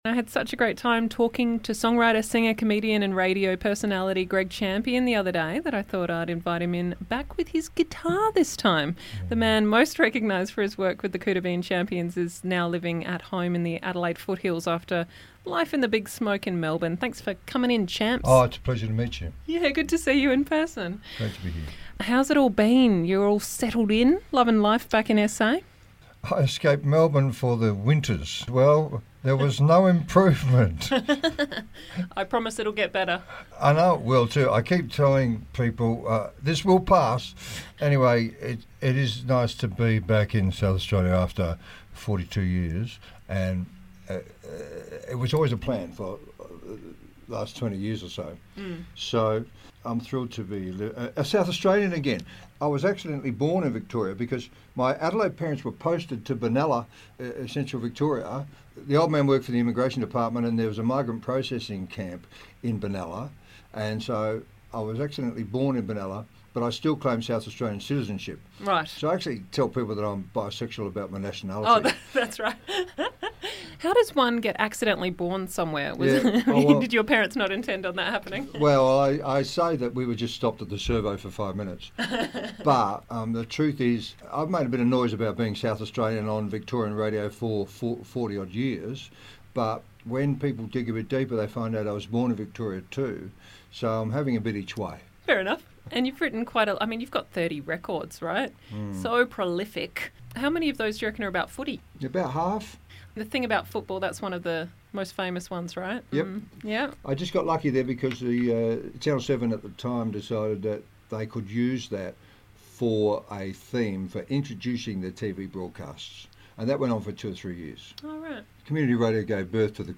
for a chat in studio and a couple of live acoustic tunes.